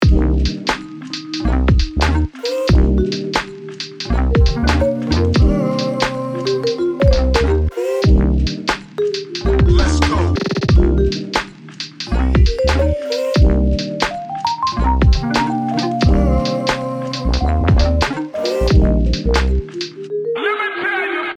New beat